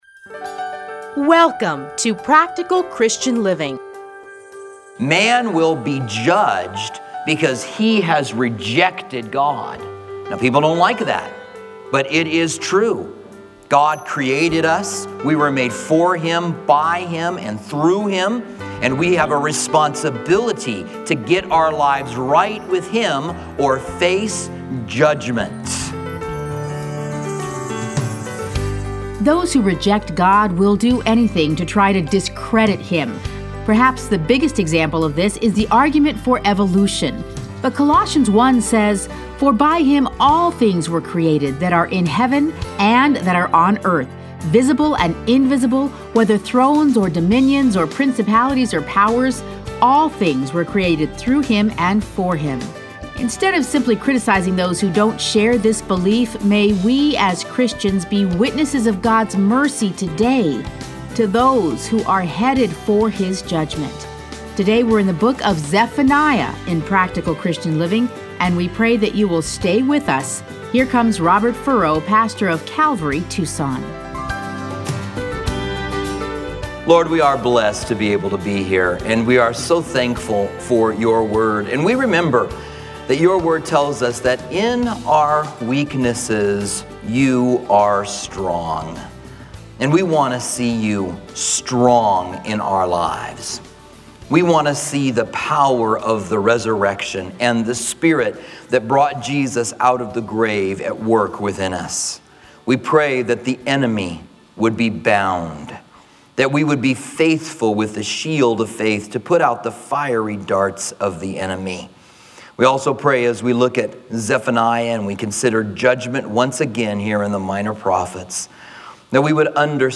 Listen to a teaching from Zephaniah 1.